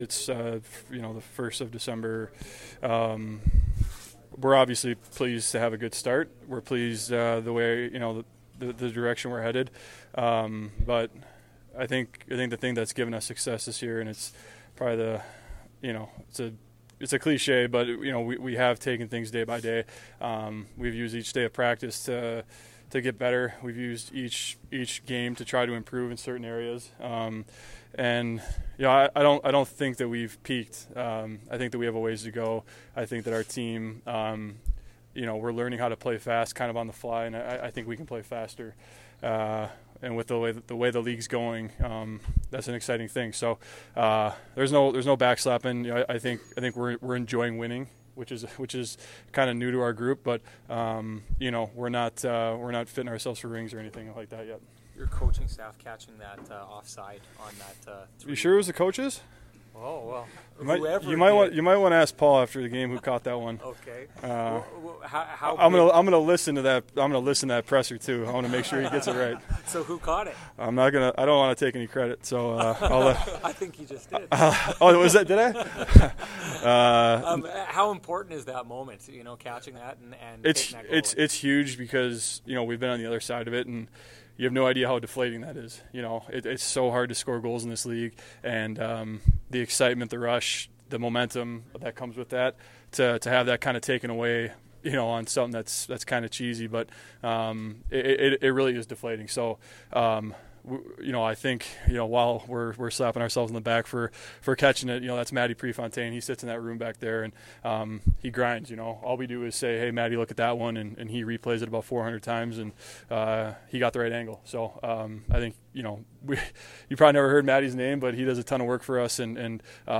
December-1-2017-Captain-Blake-Wheeler-post-game.mp3